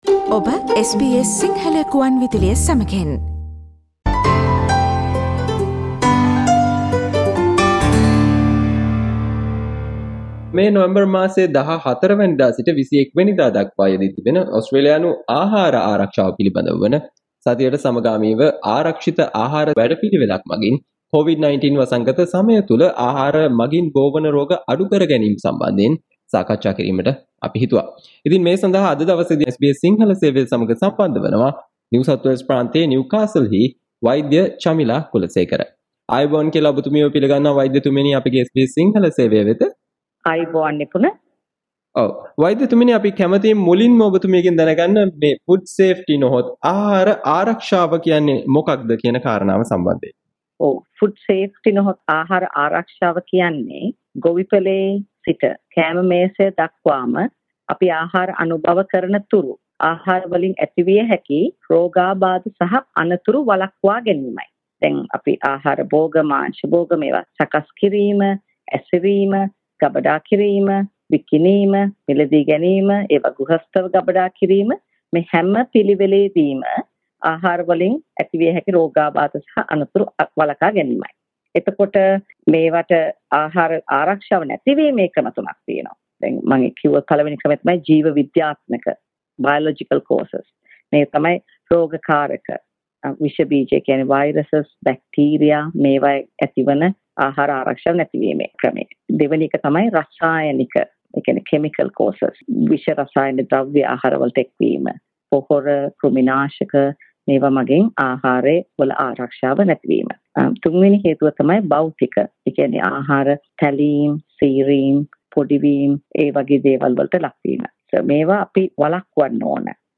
SBS Sinhala discussion